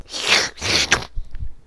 SOUND thwpp